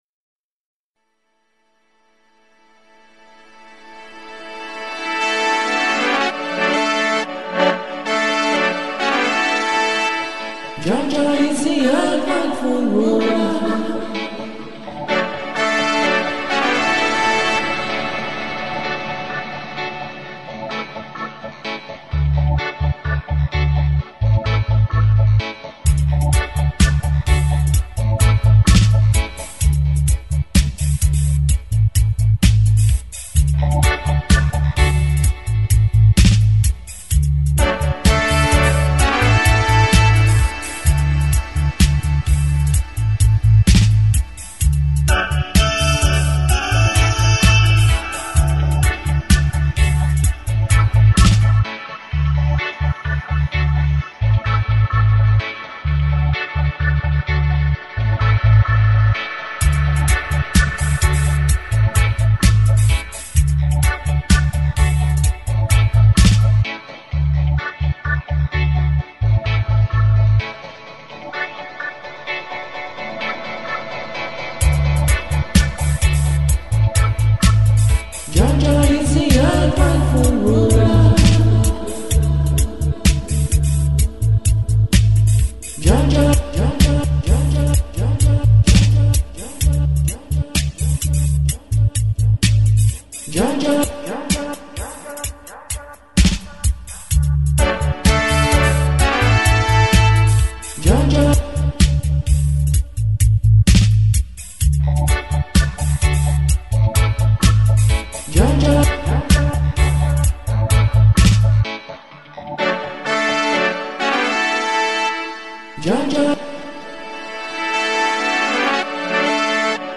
dub mixes